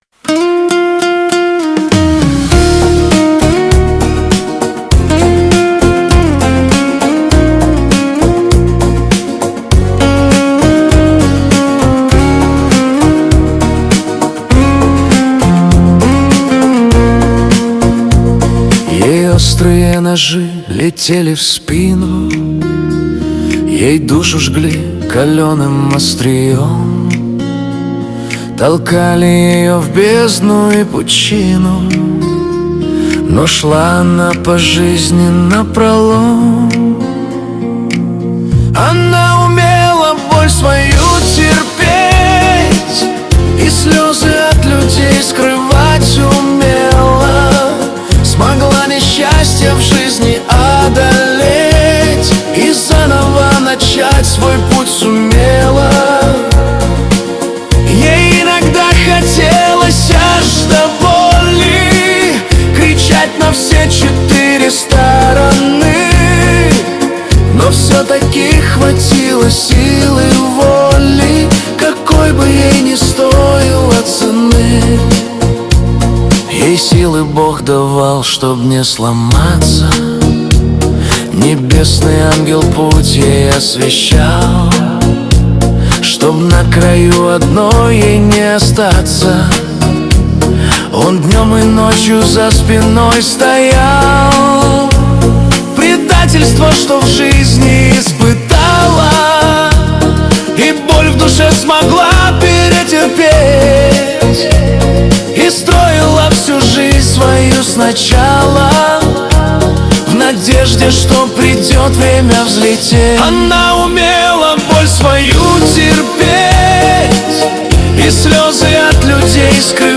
Качество: 262 kbps, stereo
Нейросеть Песни 2025, Стихи